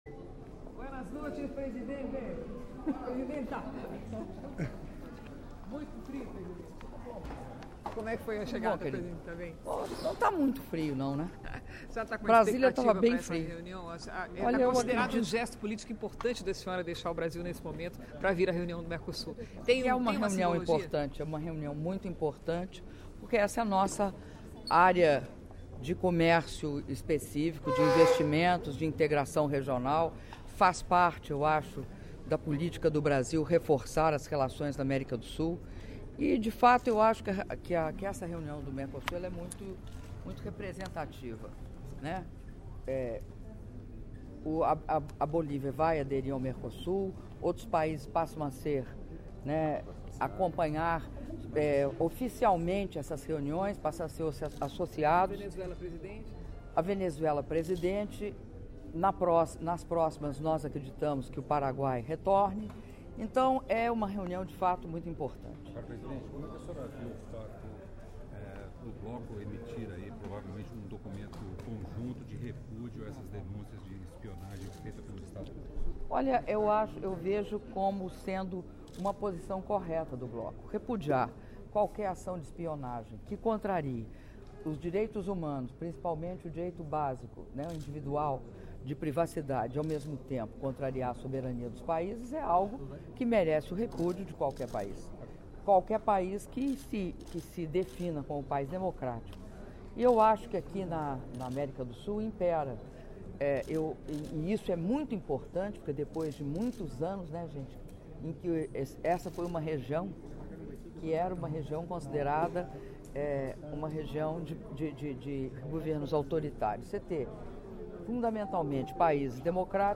Entrevista concedida pela Presidenta da República, Dilma Rousseff, na chegada ao Uruguai para Reunião de Cúpula do Mercosul
Montevidéu, Uruguai, 11 de julho de 2013